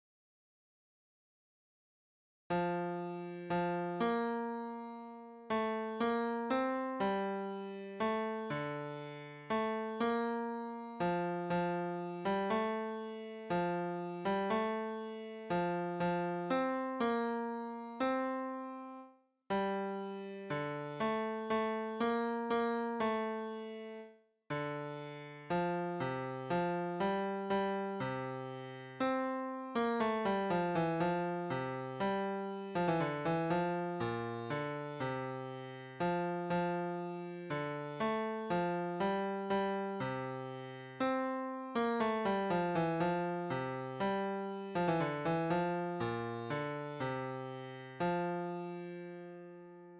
vierstemmig gemengd zangkoor
In bijlagen de gezongen versie van het lied, ook erbij de verschillende stemmen